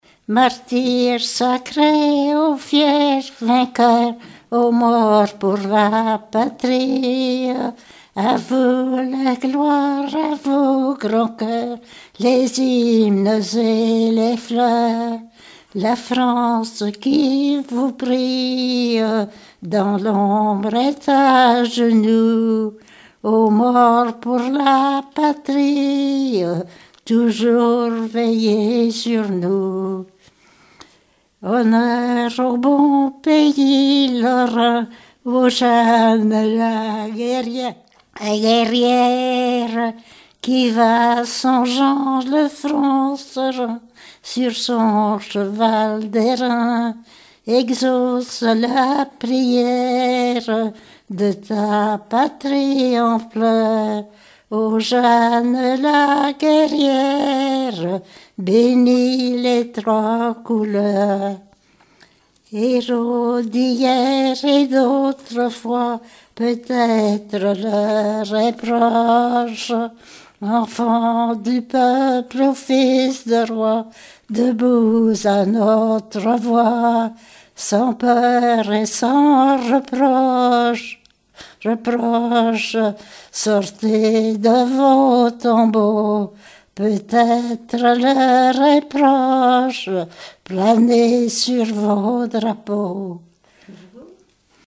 Genre strophique
Enquête Arexcpo en Vendée-C.C. Deux Lays
chansons d'écoles et populaires
Pièce musicale inédite